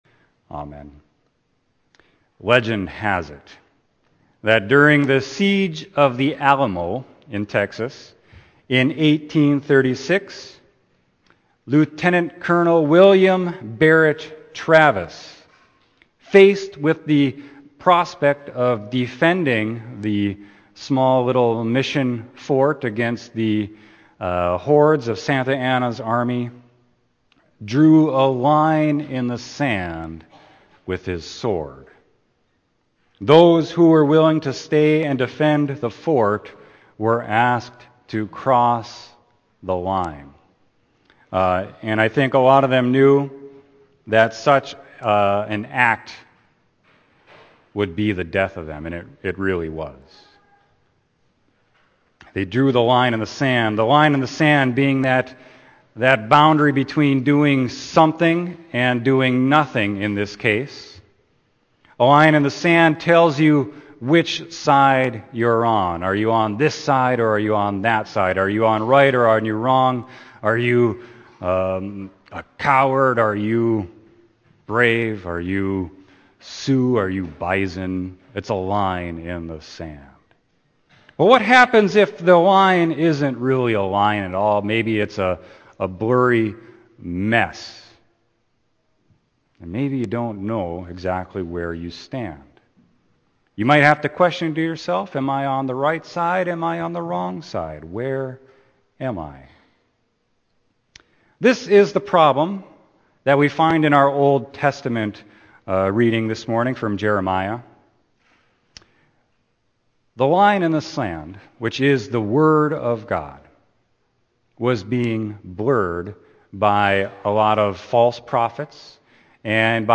Sermon: Luke 12.49-56